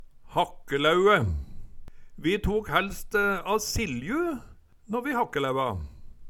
hakkelæue - Numedalsmål (en-US)